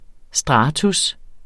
Udtale [ ˈsdʁɑːtus ]